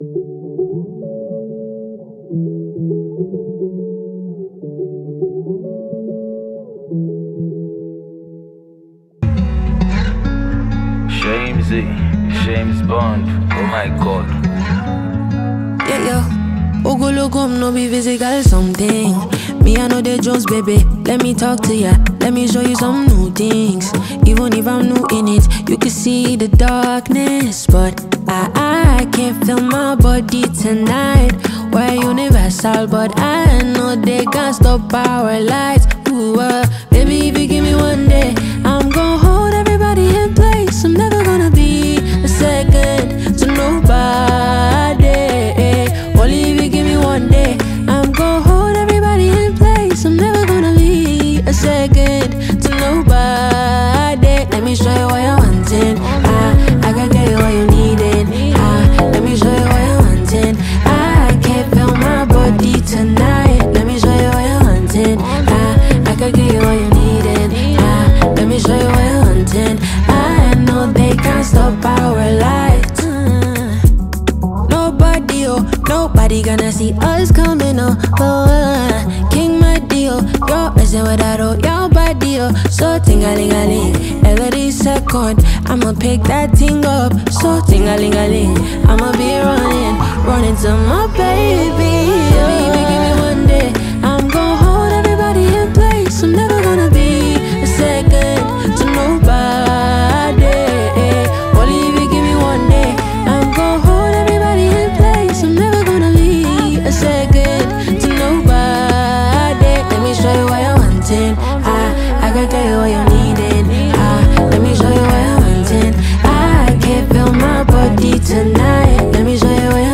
Multi-talented Nigerian Female Songstress and producer
catchy jam